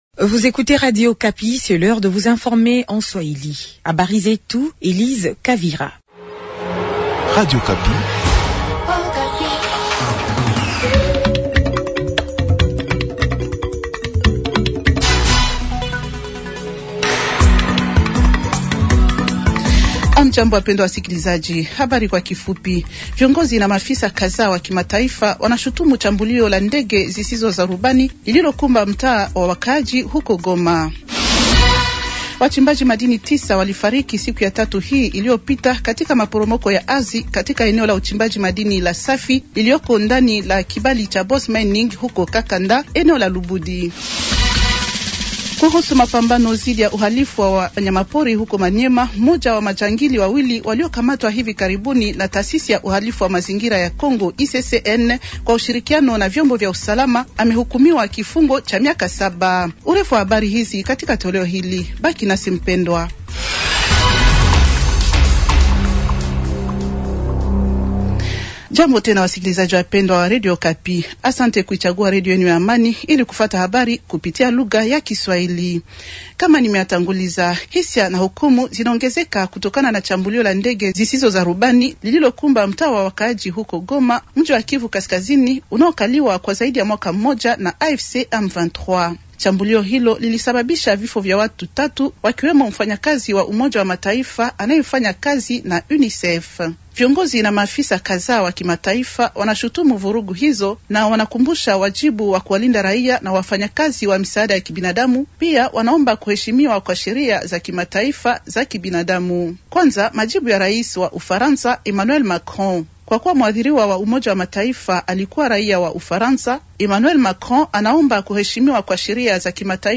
Journal swahili de vendredi matin 130626